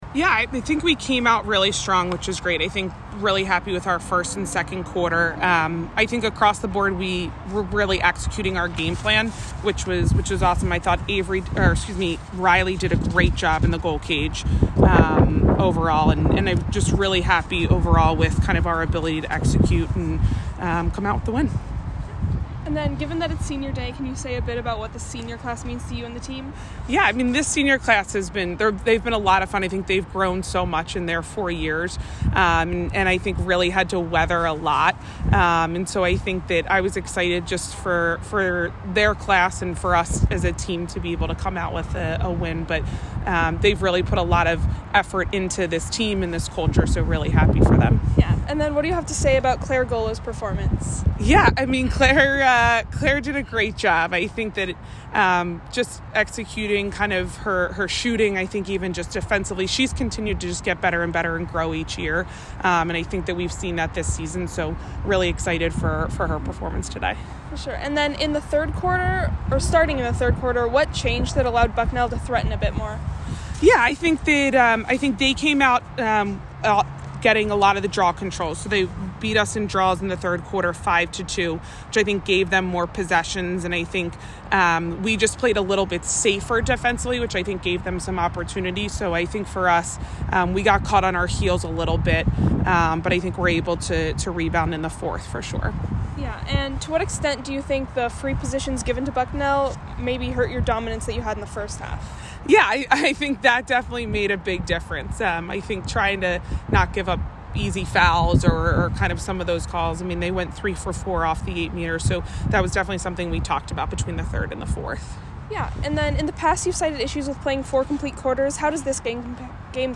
Bucknell Postgame Interview